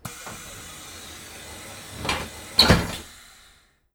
AbfahrtHaltestelleCoach.wav